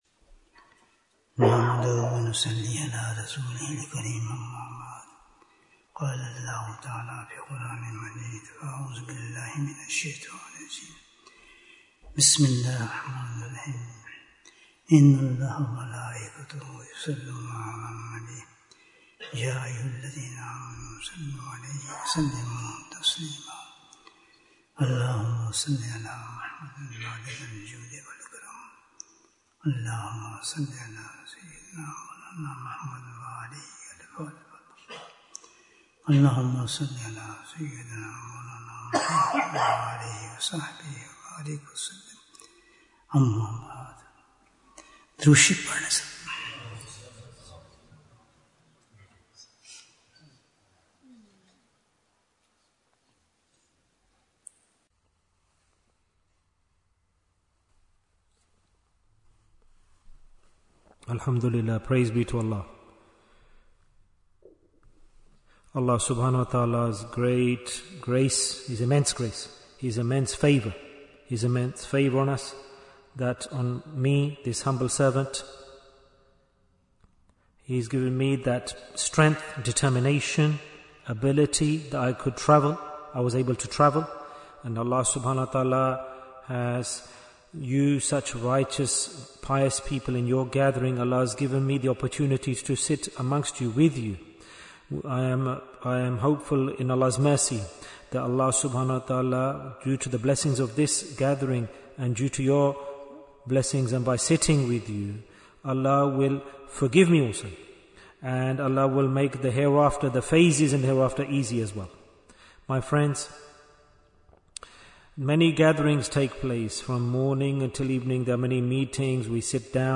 Majlis-e-Dhikr in Burnley Bayan, 84 minutes25th October, 2025